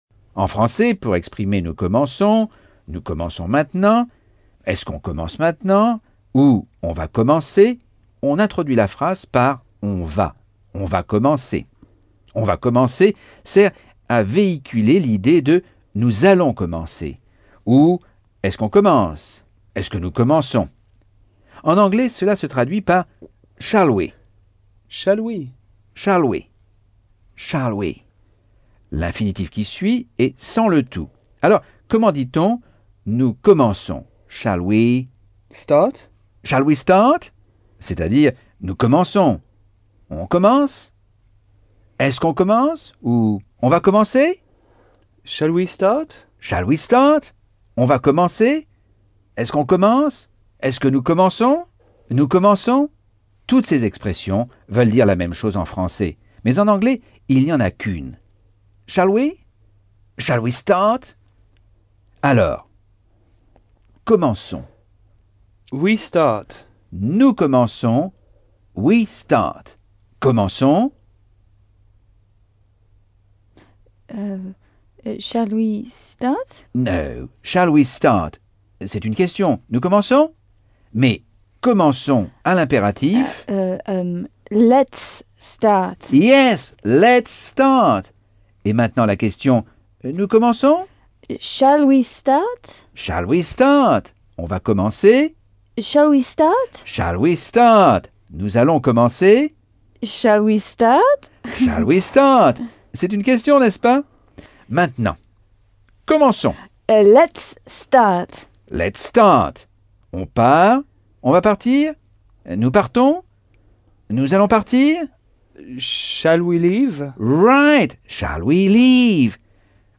Leçon 6 - Cours audio Anglais par Michel Thomas - Chapitre 5